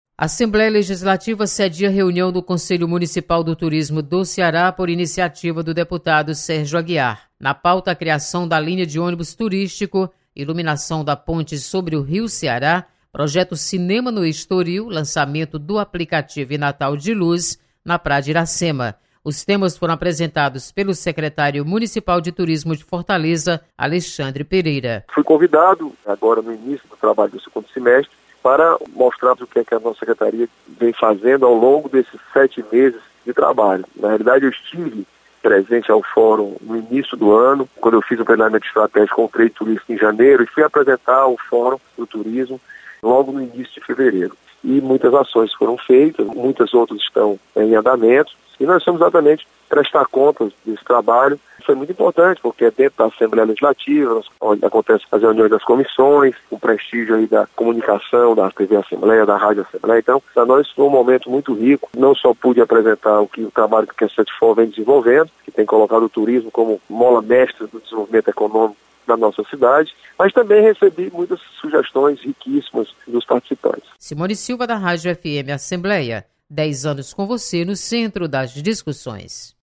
Conselho Municipal de Turismo do Ceará realiza reunião na Assembleia Legislativa. Repórter